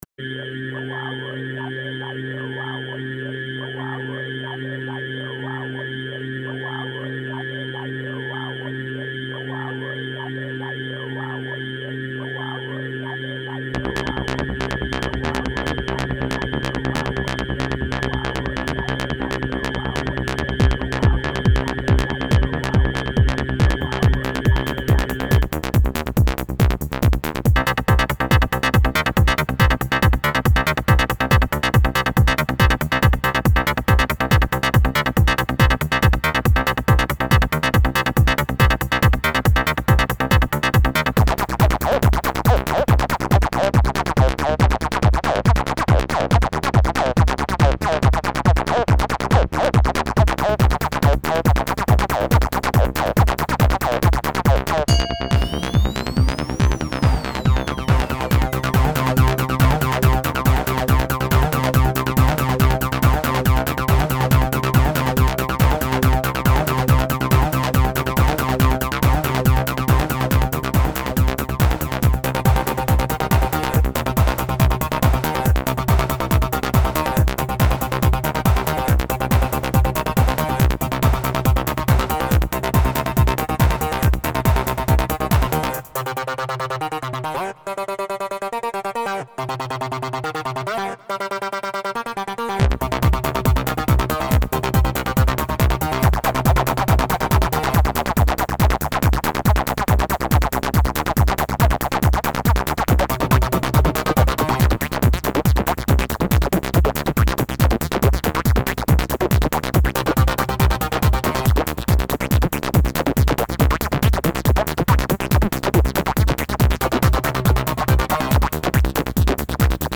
サイケ感でてますか?短い間だけでも旅ができるような曲を目指してます。
展開忙しい割りにメリハリがないんだよ。
まず、なんかゲイン低くないすか？
まだトランスってより自作アシッドハウス的にしか感じられなかった。